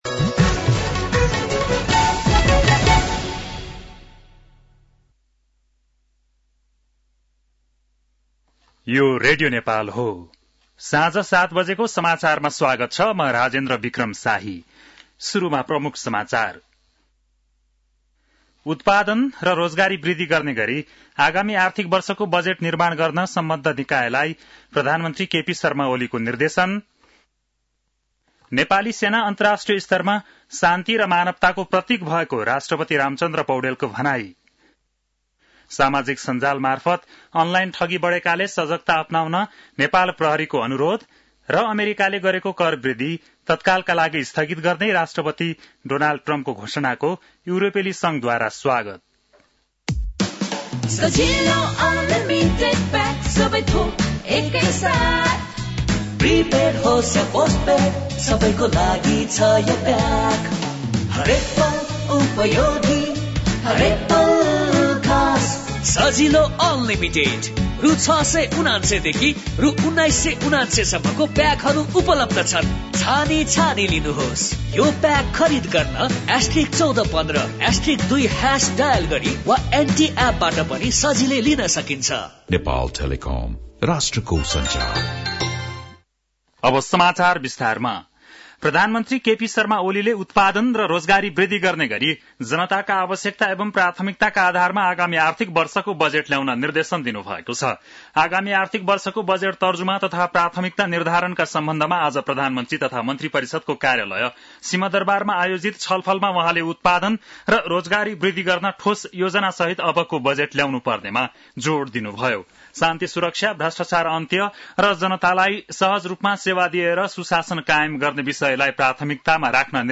बेलुकी ७ बजेको नेपाली समाचार : २८ चैत , २०८१
7-pm-nepali-news-12-28.mp3